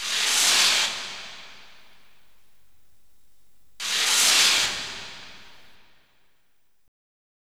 64BELLS-HH-L.wav